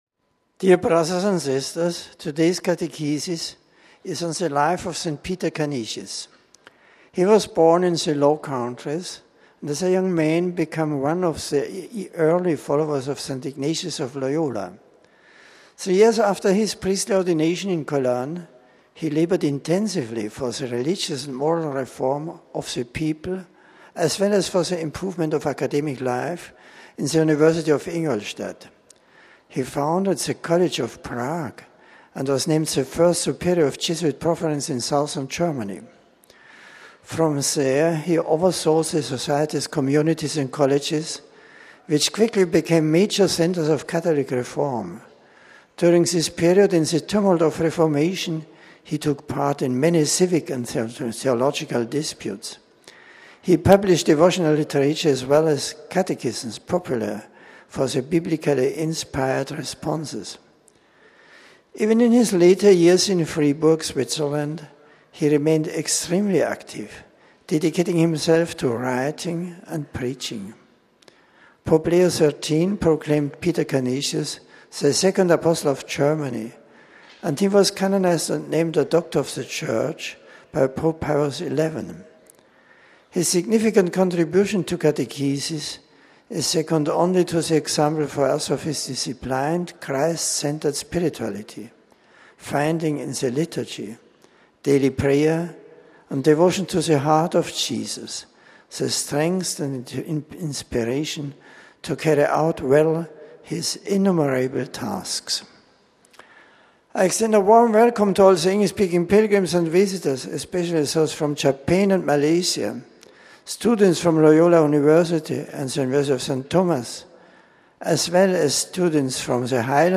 The general audience of Feb. 9 was held in the Vatican’s Paul VI Audience Hall. A scripture passage was read in several languages. An aide greeted the Pope on behalf of the English-speaking pilgrims, and presented the various groups to him. Pope Benedict then delivered a discourse in English.